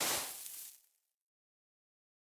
brush_sand_complete5.ogg